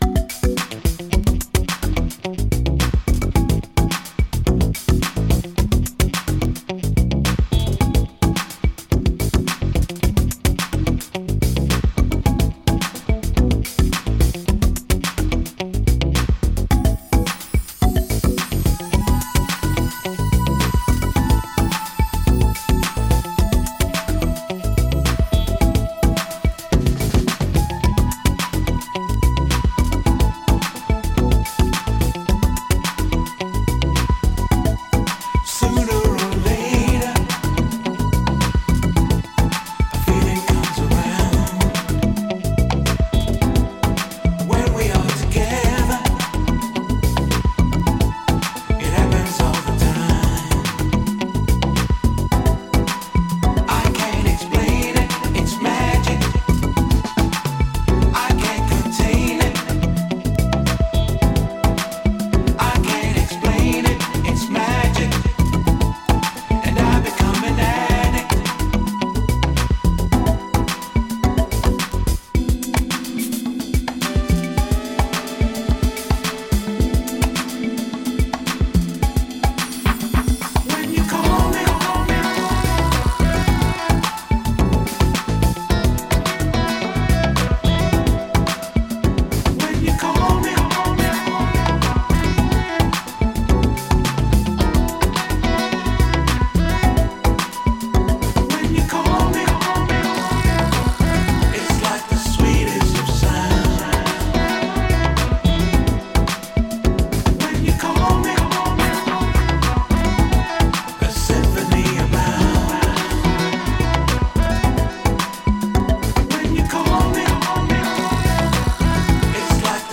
ジャンル(スタイル) FUNK / SOUL / JAZZ